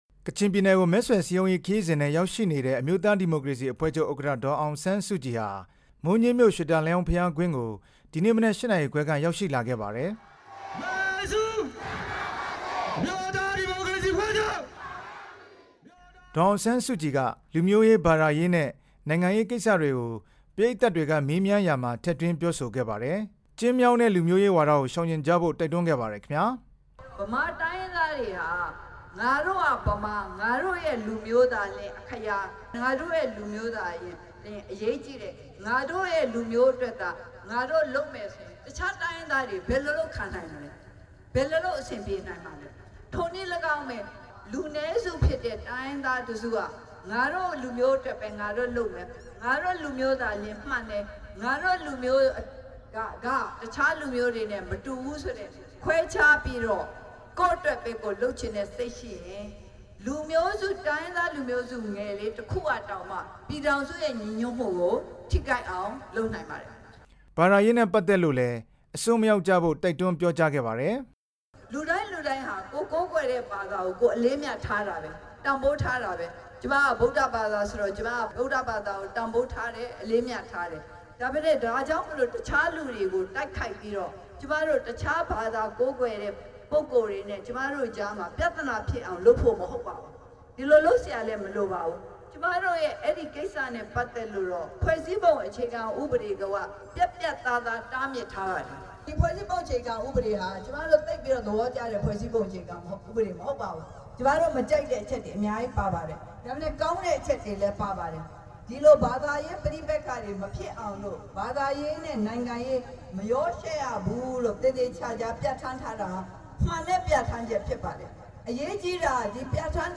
ဒေါ်အောင်ဆန်းစုကြည်ရဲ့ မိုးညှင်းနဲ့ အင်းတော်ကြီးဒေသ လူထုဟောပြောပွဲ